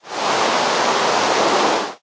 rain2.ogg